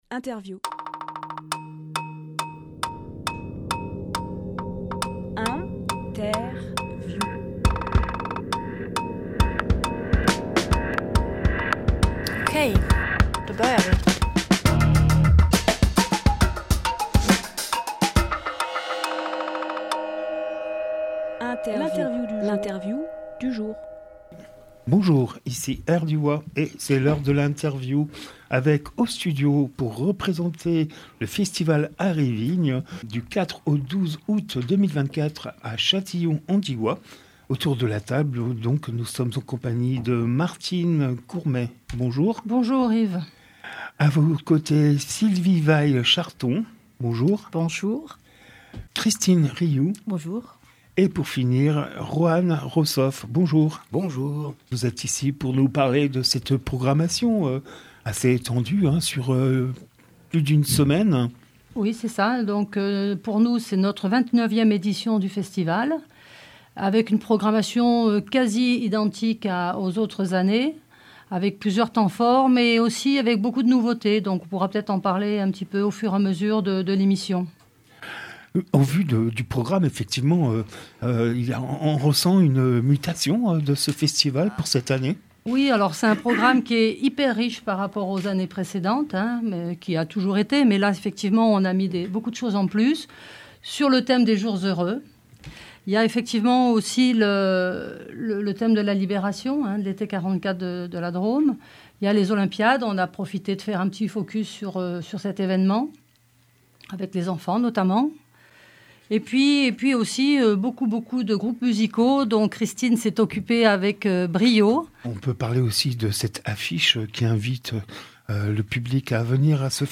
Emission - Interview Festival Arts et Vigne #22 Publié le 1 août 2024 Partager sur…
Lieu : Studio Rdwa